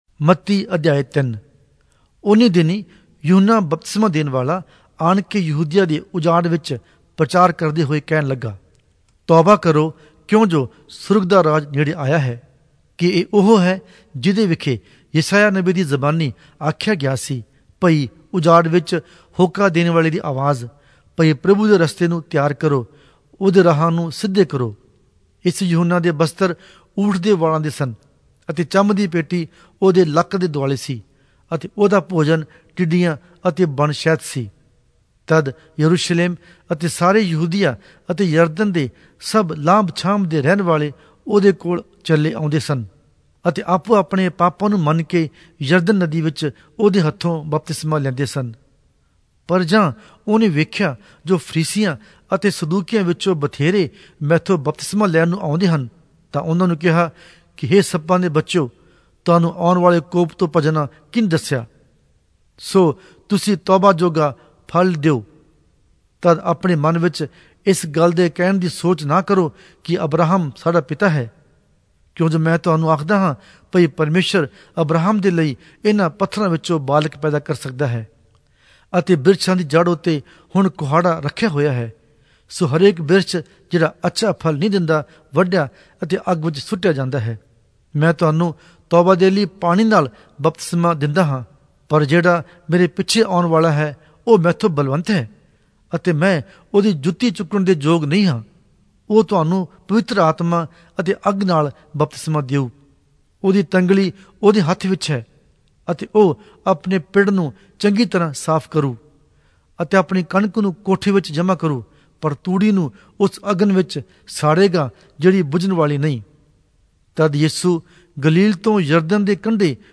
Punjabi Audio Bible - Matthew 19 in Litv bible version